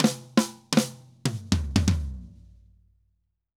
Drum_Break 120_3.wav